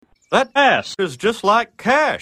File Type : Funny ringtones